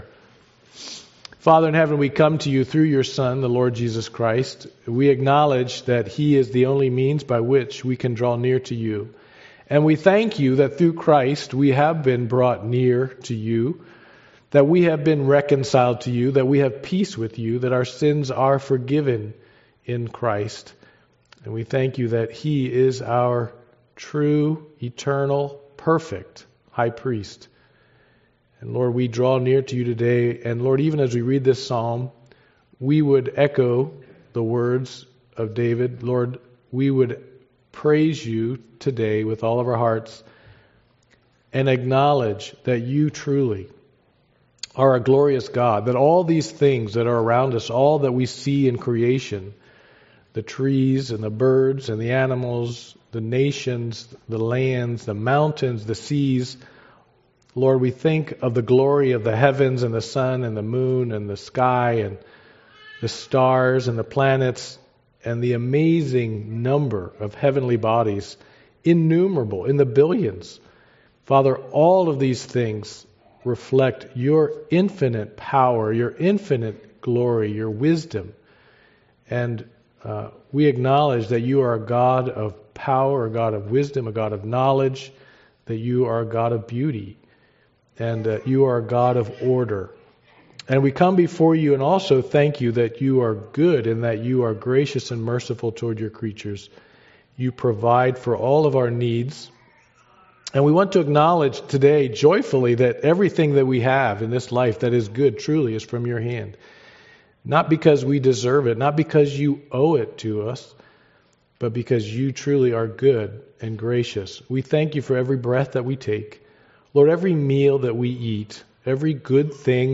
Unity in Christ Passage: John 17 Service Type: Sunday Morning Worship « Corporate Worship 58 David Flees from his Son